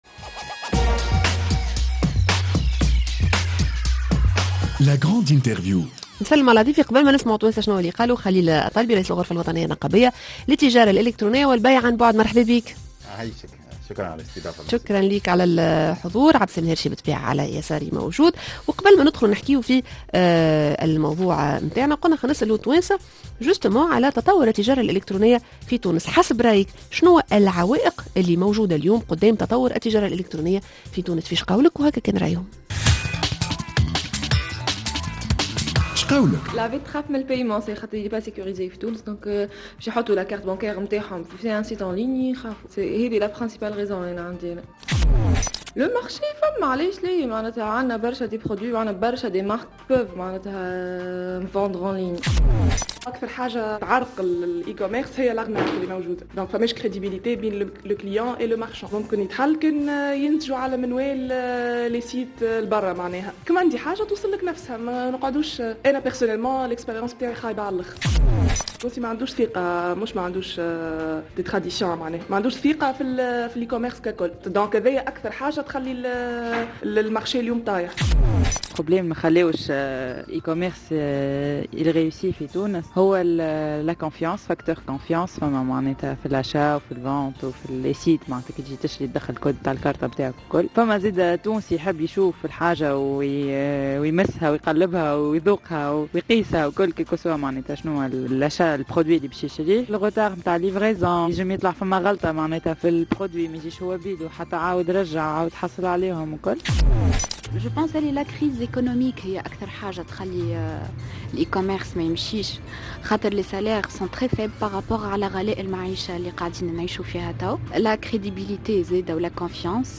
La grande interview: التجارة الإلكترونية مفتاح لتطوير الإقتصاد؟